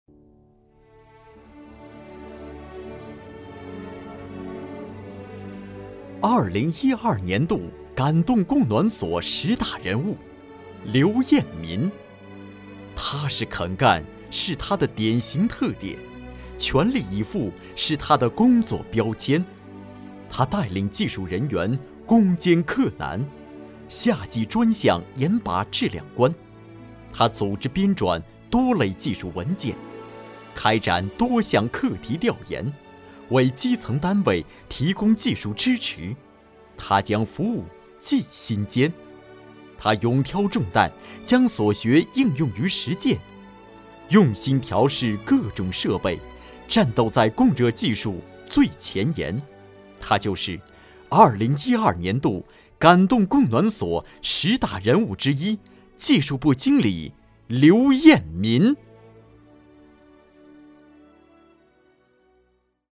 国语中年沉稳 、男专题片 、宣传片 、30元/分钟男3 国语 男声 专题片-安徽电视台 江淮聚焦 节目 解说 沉稳